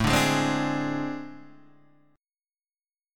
AmM7bb5 chord {5 5 6 5 3 4} chord